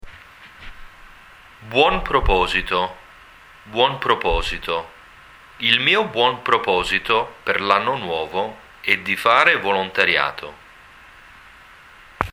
Click on the audio file to download and hear the pronunciation of the phrase and sentence.